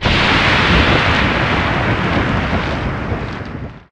Sound of a geyser venting
Geyser-SWE1R.ogg